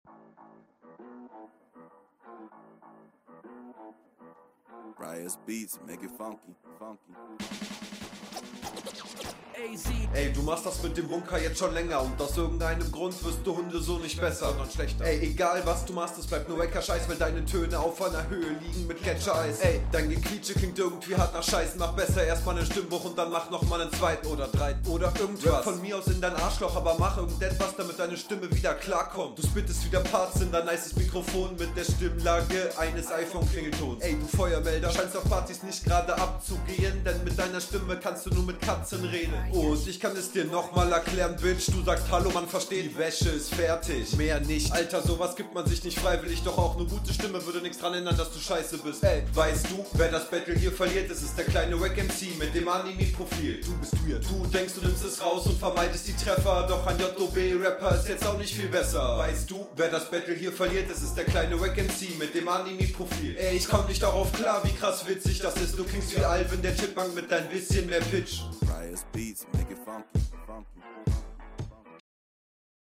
Flow: Ist eigentlich okay aber Abmische zieht alles von einer guten 2 auf eine 5 …
Flow: finde ich größtenteils safe. Nichts aufregendes, eher monoton und zwei drei kleine nicht 100% …